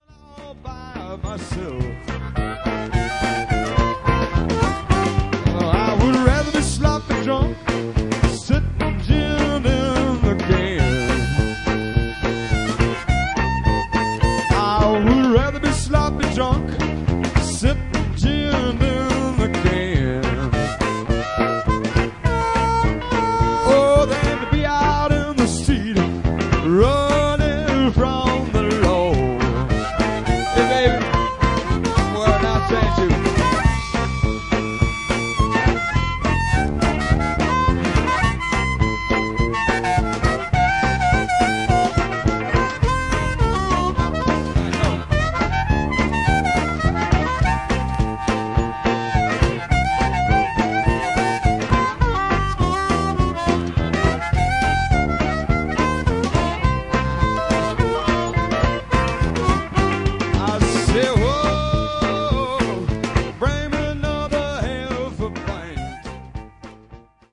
Classic live recordings from our vast back catalogue.